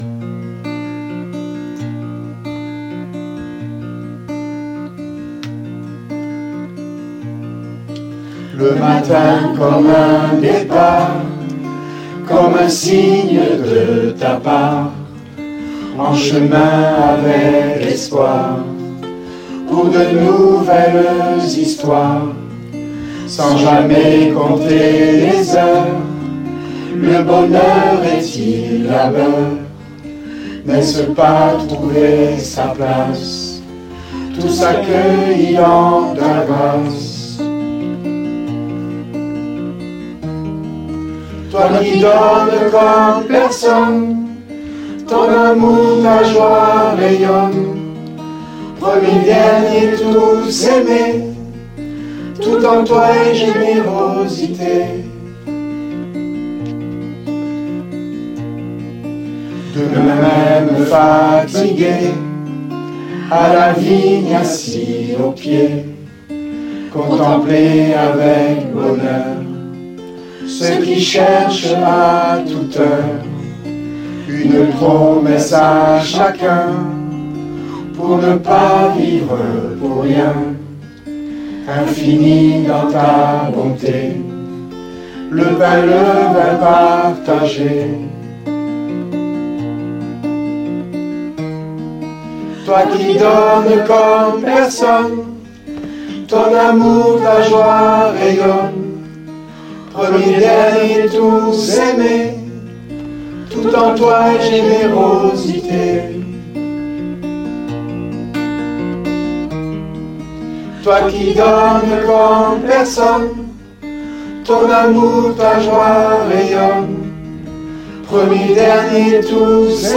Les  chants
Toi qui donnes   Création  réalisée par l’Ensemble Narbonne – Carcassonne